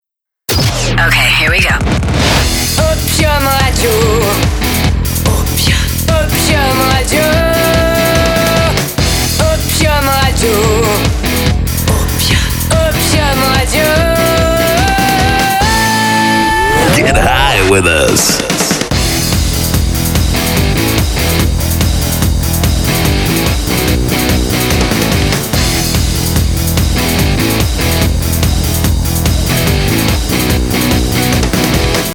Tous types de voix pour e-learning, institutionnel, promo douce.
Sprechprobe: Sonstiges (Muttersprache):
My voice is perfect for smooth commercials or institutional recordings.